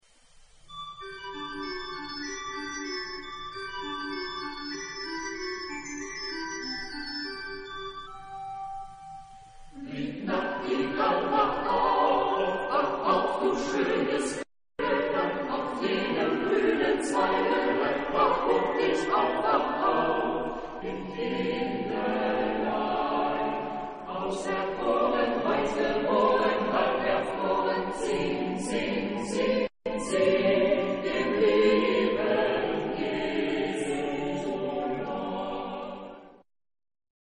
Epoque: 17th century  (1650-1699)
Genre-Style-Form: Christmas song ; Secular ; Sacred
Type of Choir: SATB  (4 mixed voices )
Tonality: G major